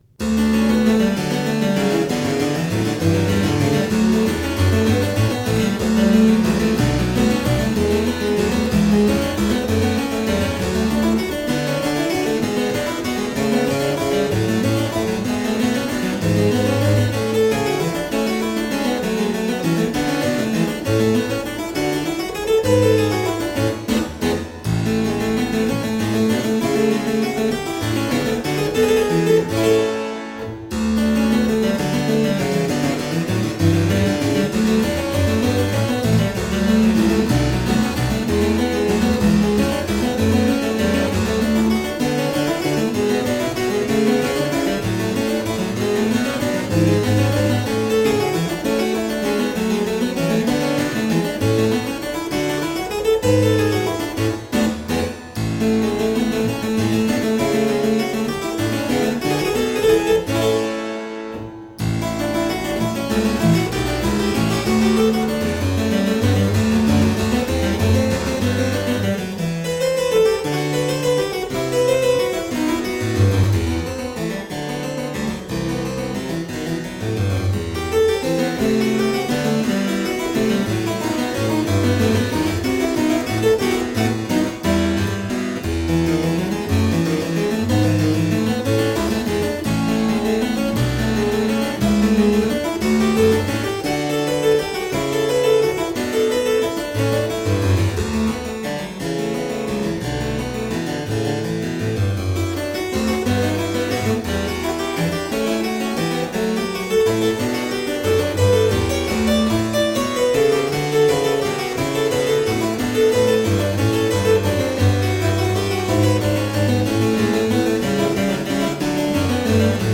three different beautiful harpsichords
Classical, Baroque, Instrumental, Harpsichord